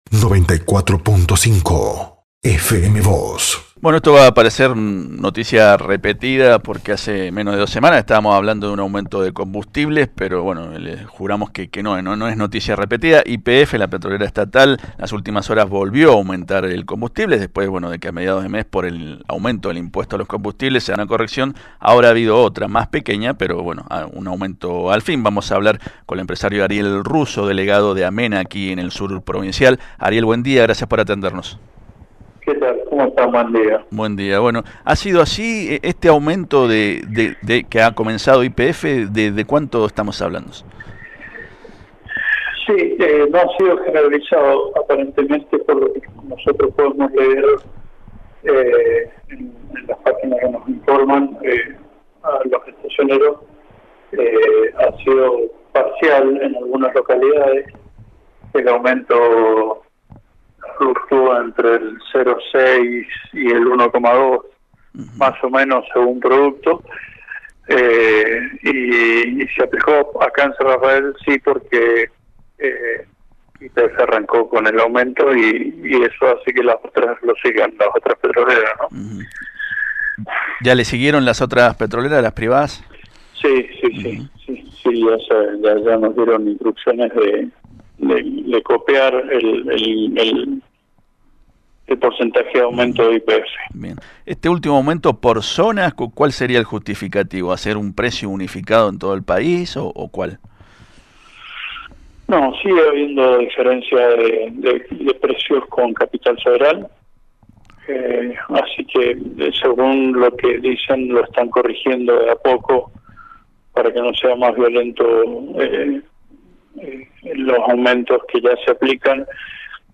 dijo en diálogo con FM Vos (94.5) y Diario San Rafael que la suba “fluctúa entre el 0,6 y el 1,2 por ciento”.